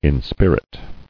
[in·spir·it]